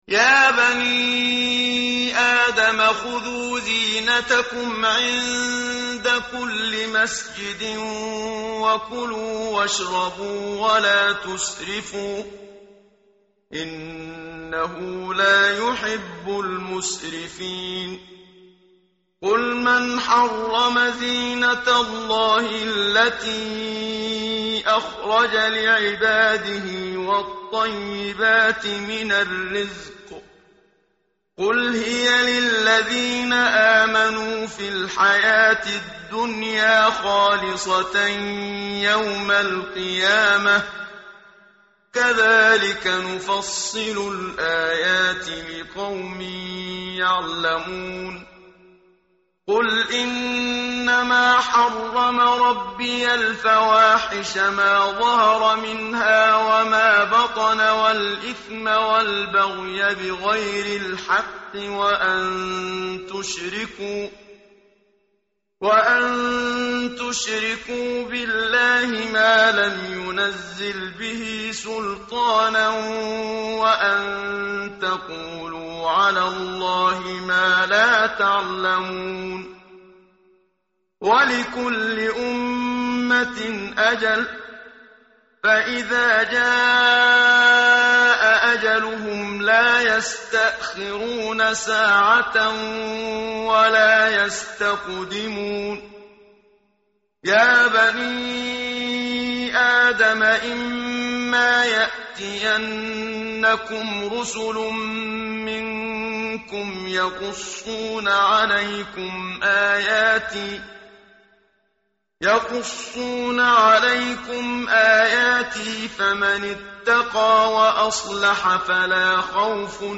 متن قرآن همراه باتلاوت قرآن و ترجمه
tartil_menshavi_page_154.mp3